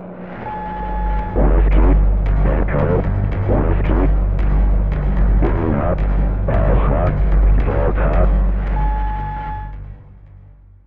Play special sound when you get chosen as traitor in SSS.
tatoralert.ogg